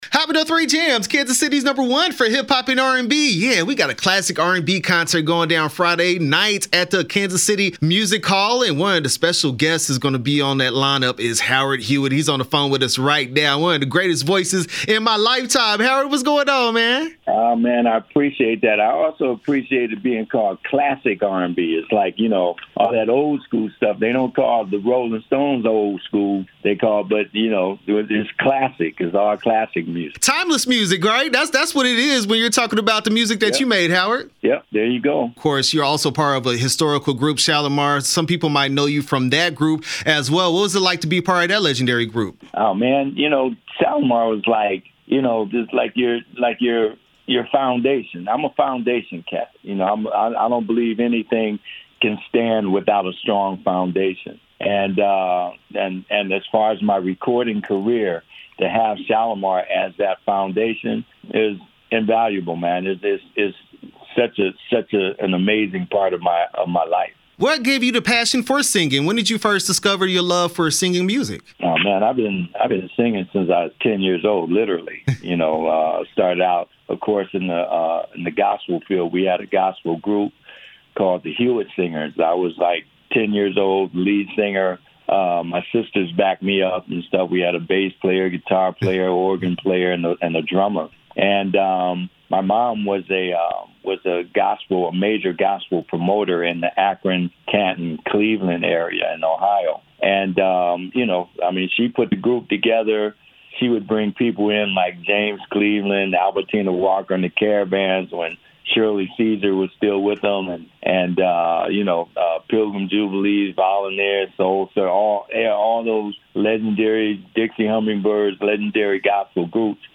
Howard Hewitt interview 6/29/22